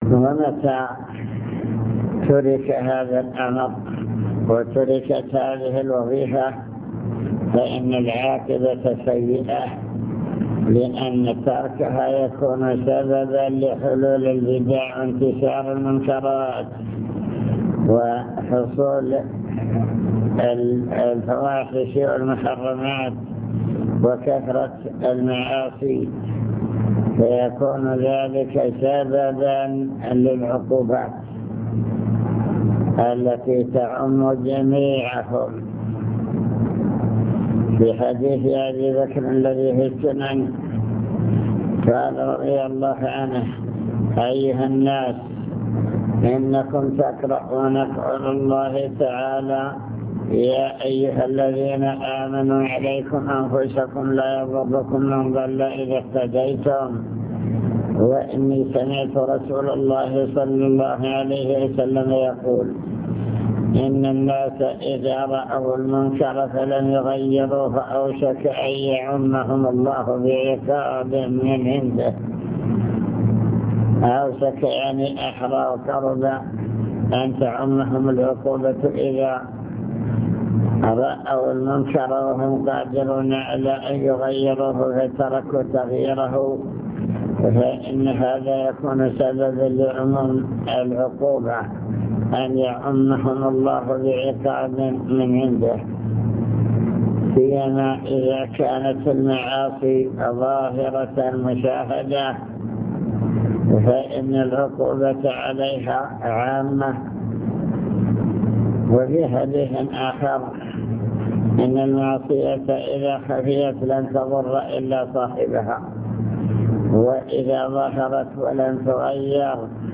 المكتبة الصوتية  تسجيلات - لقاءات  كلمة في رئاسة الأمر بالمعروف الدعوة إلى الله تعالى